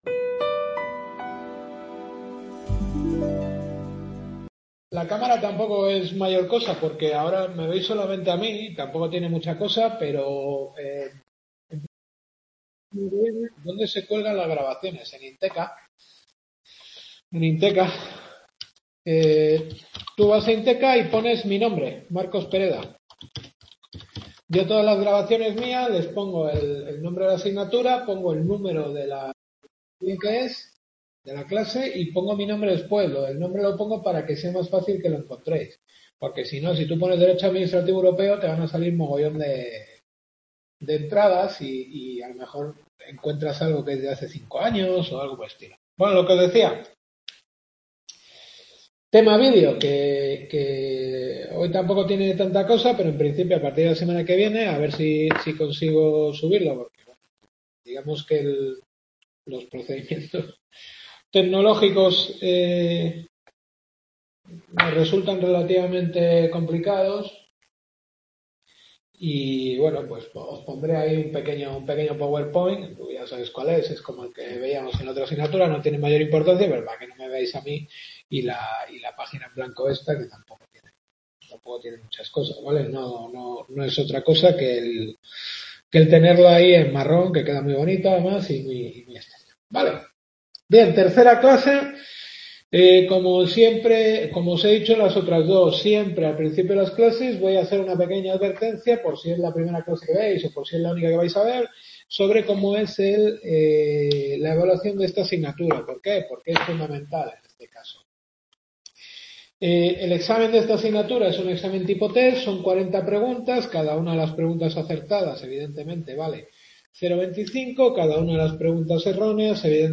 Derecho Administrativo Europeo. Tercera Clase.